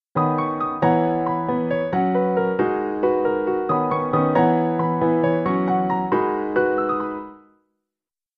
何もフィルターを通していない状態の音
うん、これはまごうことなきピアノの音だよっ。